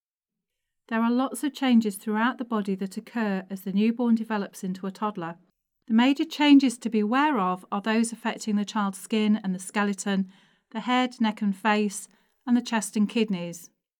Narration audio (OGG)